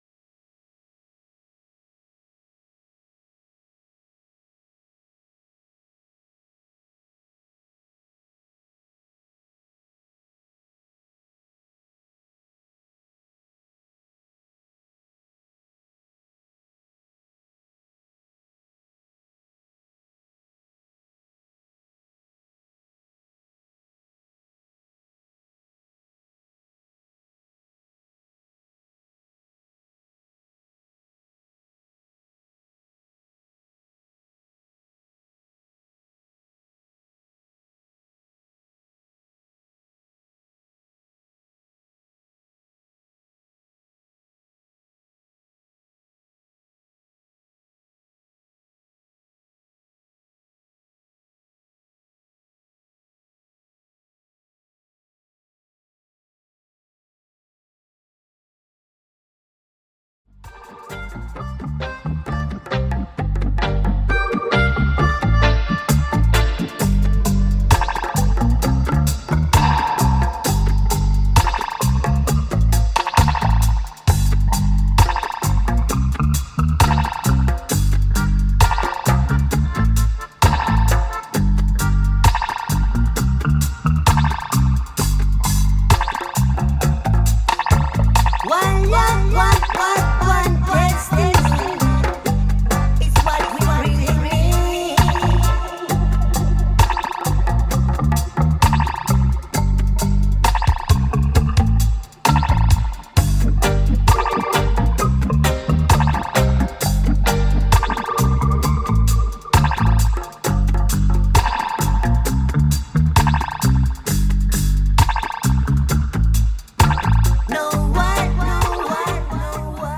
Super Melodica lead dub